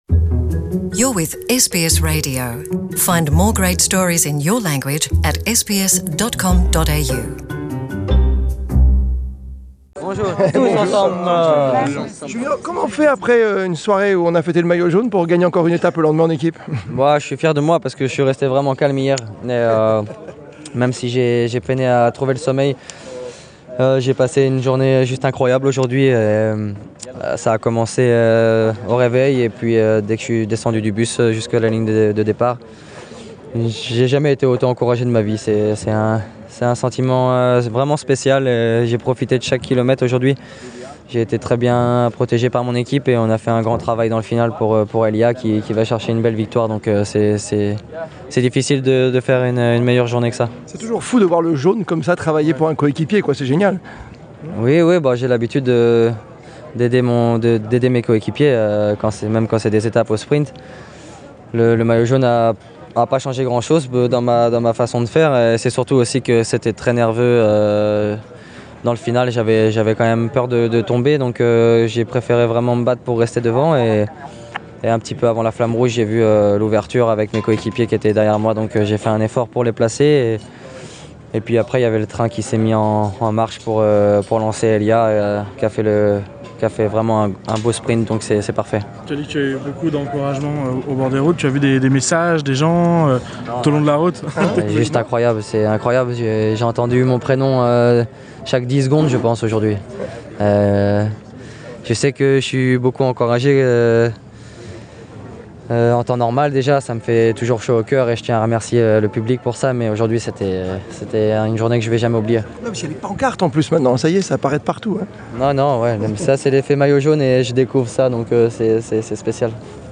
Interview avec Julian Alaphilippe, maillot jaune du Tour de France